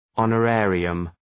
Προφορά
{,ɒnə’reərıəm}